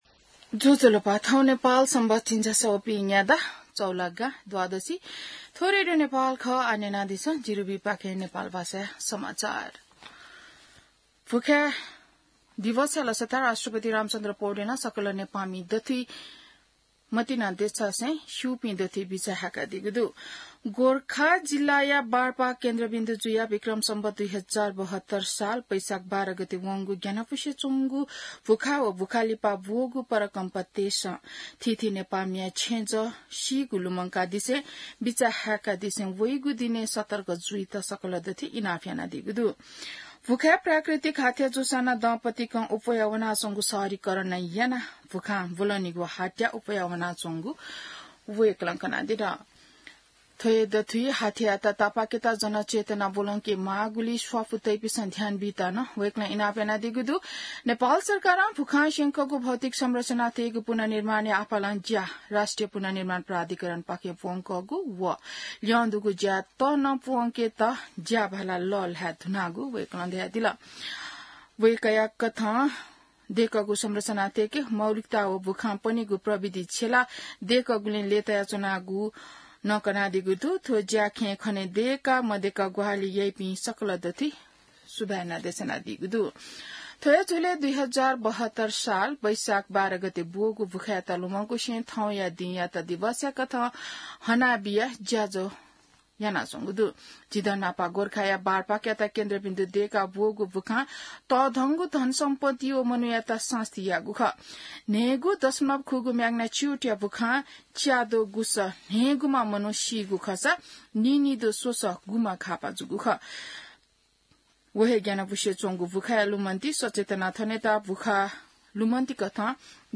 नेपाल भाषामा समाचार : १२ वैशाख , २०८२